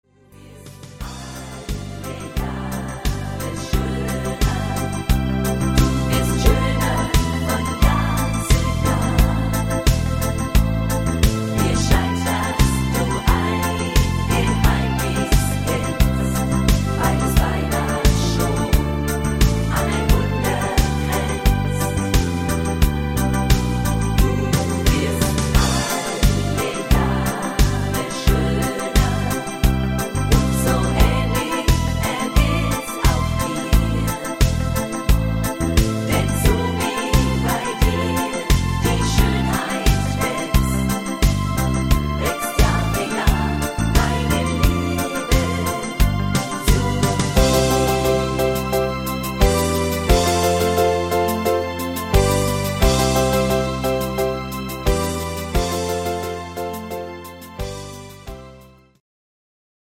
Rhythmus  Disco Shuffle
Art  Schlager 90er, Deutsch